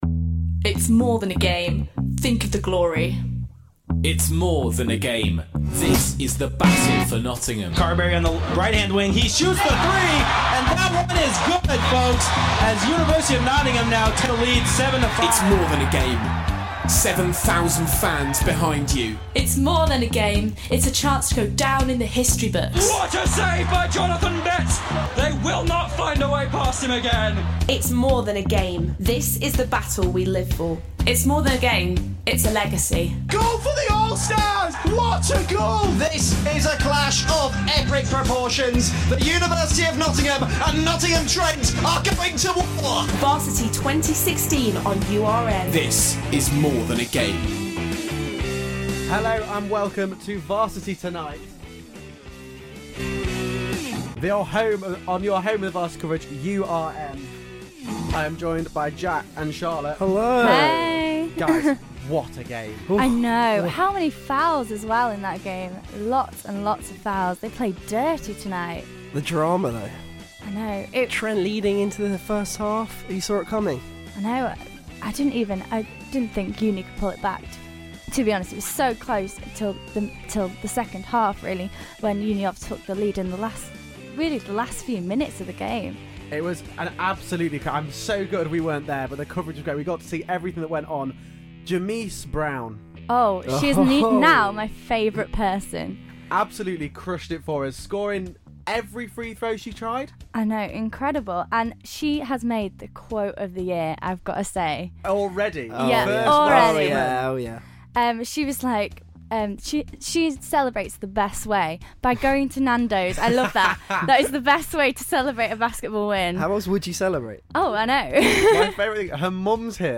On the night of the first meet up of Uni of and Trent the guys listen to some unusual interview, check in with Roxy the mystic hamster and chat to presenters live at the Motorpoint Arena.